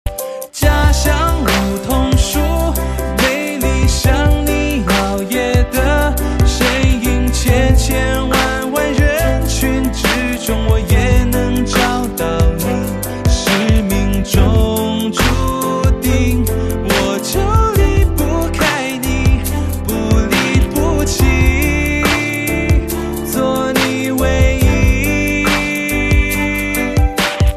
M4R铃声, MP3铃声, 华语歌曲 66 首发日期：2018-05-14 12:27 星期一